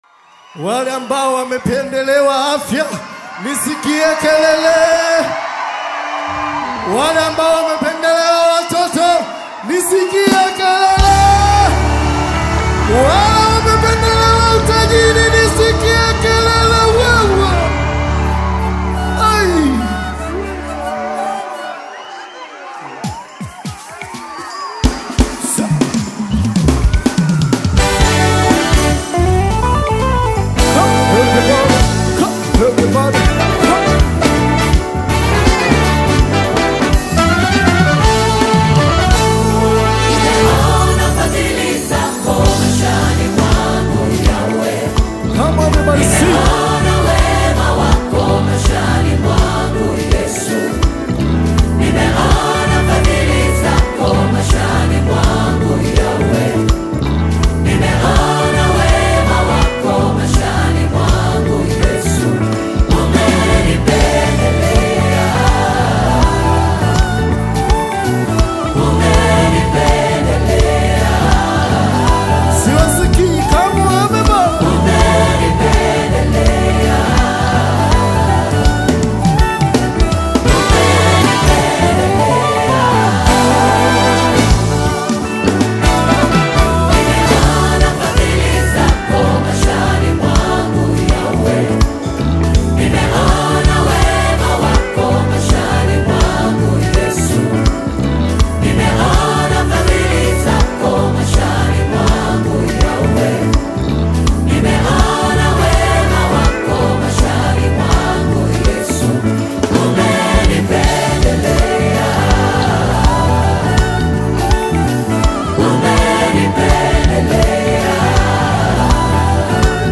gospel song
Through heartfelt lyrics and uplifting melodies